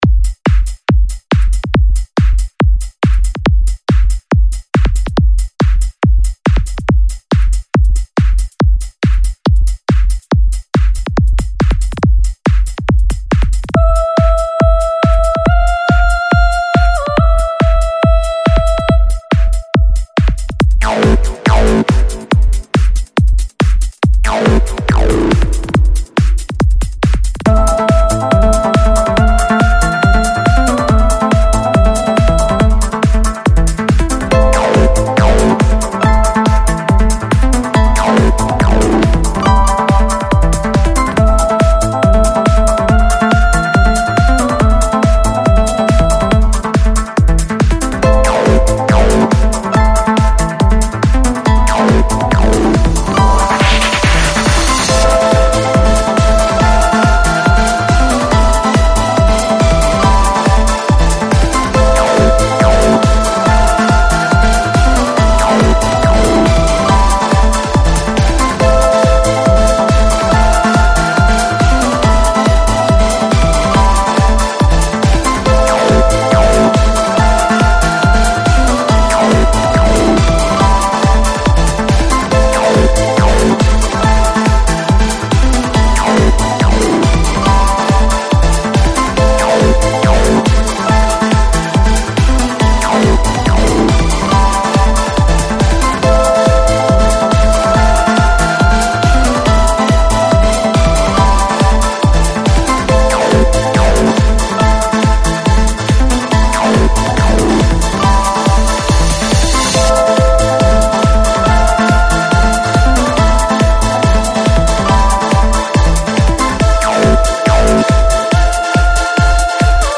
THIS IS THE PSYTRANCE RMX